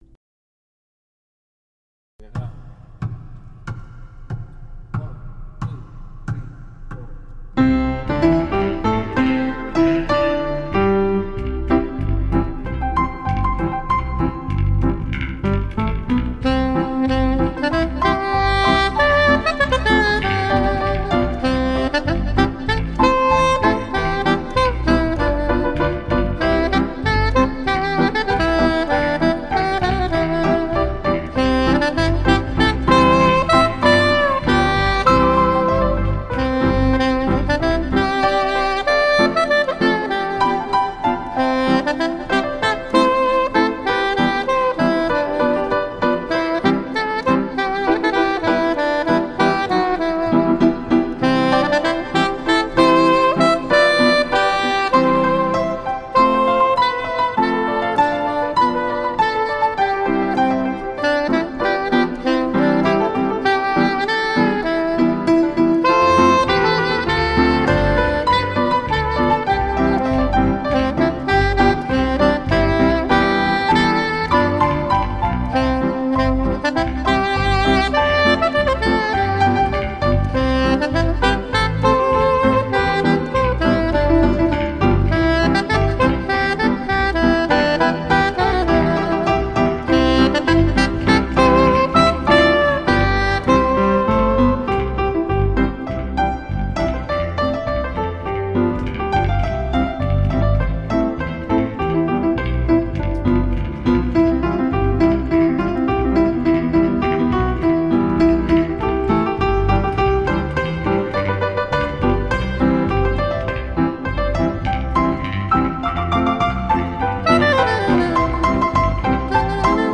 专辑歌手：纯音乐
高音萨克斯、低音贝斯、三角钢琴
演绎的专辑，洋溢贵丽的音色，夜了，酒吧的气氛，酒精，